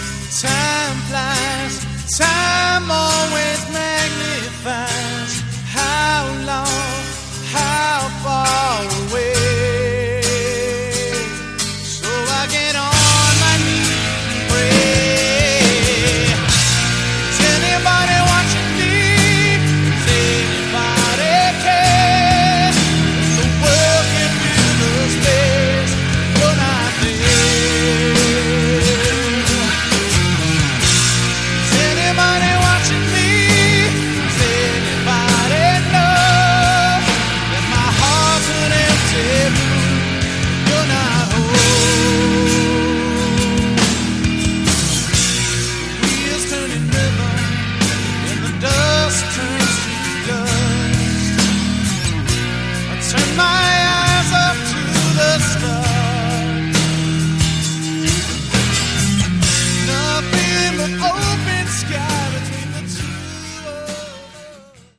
Category: Hard Rock
Drums
Bass
Guitars
Lead Vocals, Guitars